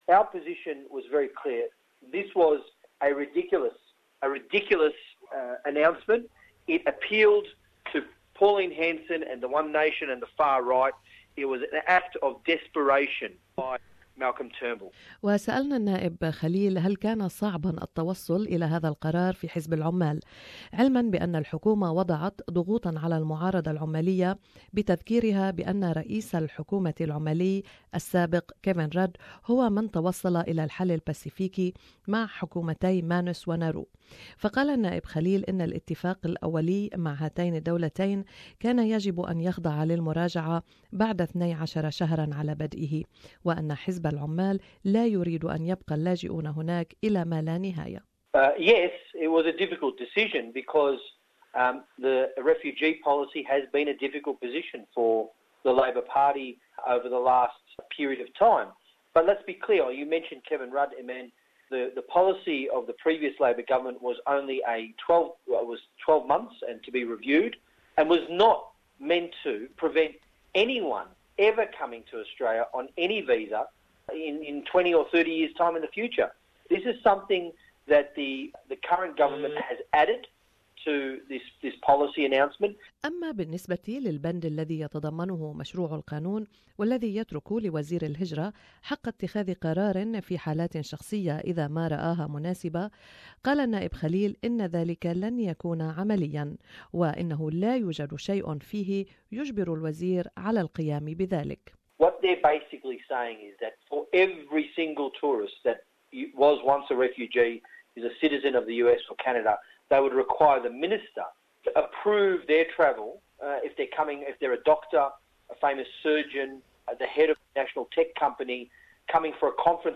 Interview with Labor MP Hon. Peter Khalil